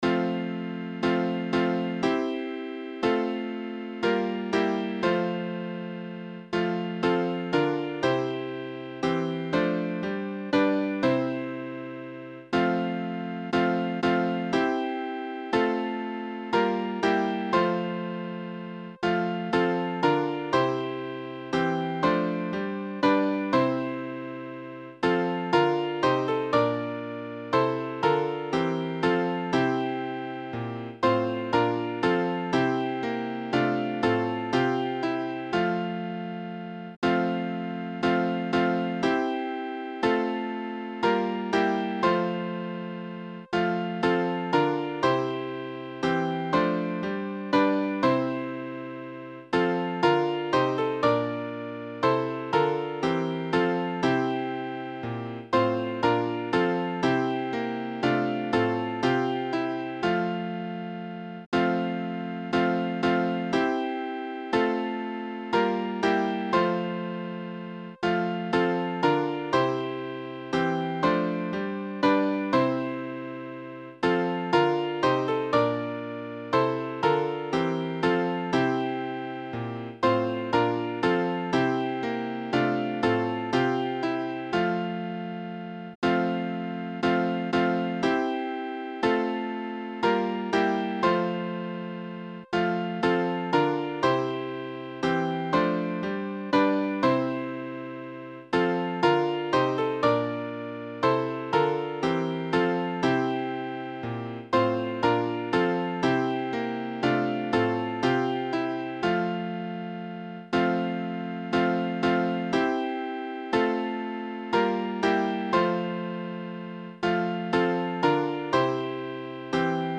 Midi File Transcriptions